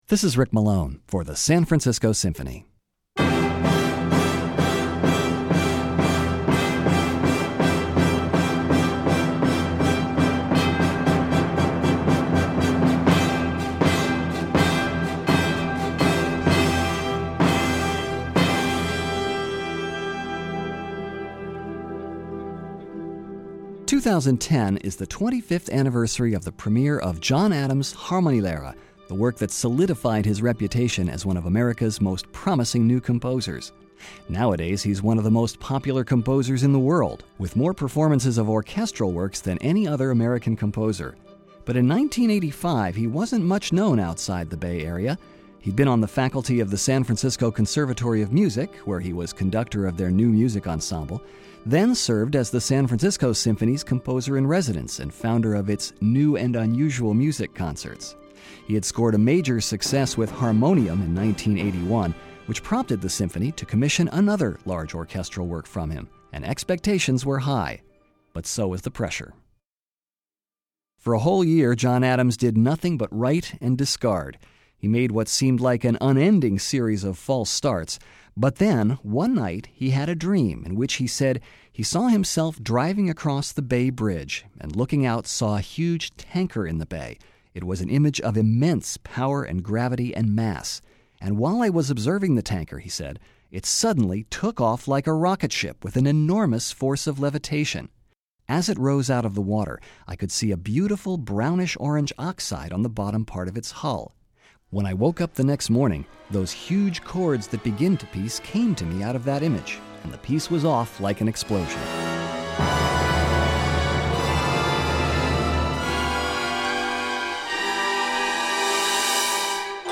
He veered off from many of his contemporaries in this work by moving from minimalism back to a more Romantic harmonic language.